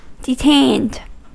Ääntäminen
Ääntäminen US Tuntematon aksentti: IPA : /dɨˈtejnd/ Haettu sana löytyi näillä lähdekielillä: englanti Käännöksiä ei löytynyt valitulle kohdekielelle.